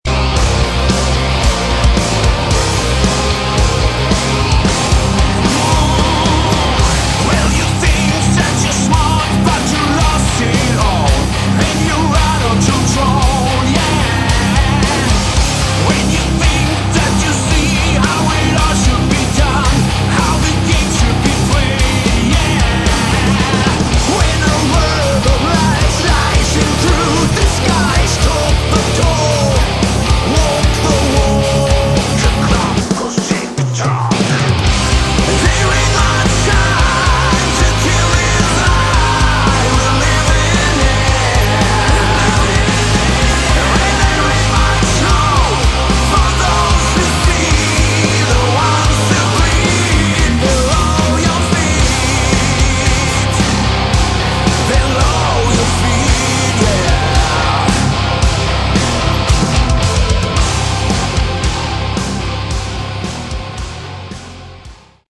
Category: Melodic Metal
vocals
guitars
bass
drums
keyboards